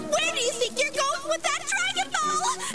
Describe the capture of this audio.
these are sounds taken from dbz i dont know what you would use them for but have a listen anyway, you could use them in a dbz game. note: to listen simply click to download right click and save target as more coming very very soon :P